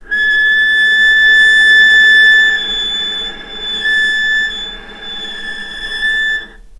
vc_sp-A6-mf.AIF